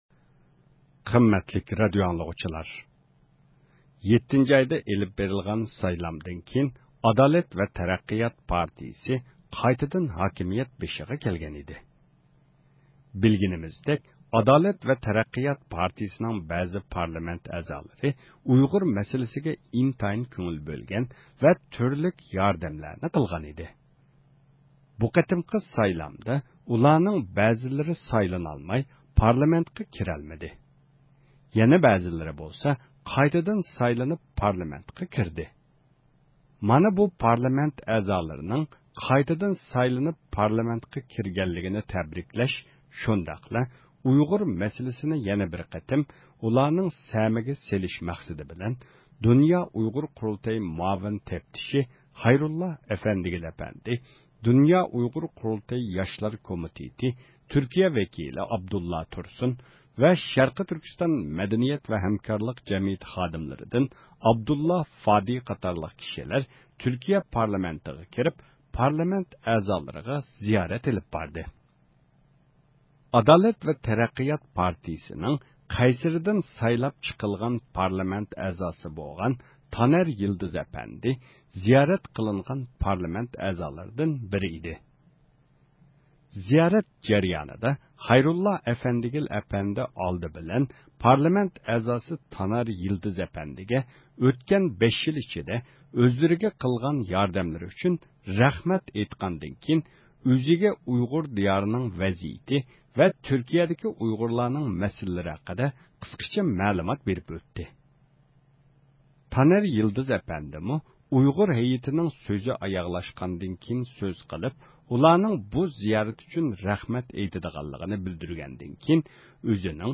بۇ ئۇچرىشىش ئاياغلاشقاندىن كېيىن بىز تانەر يىلدىز ئەپەندى بىلەن سۆھبەت ئېلىپ باردۇق .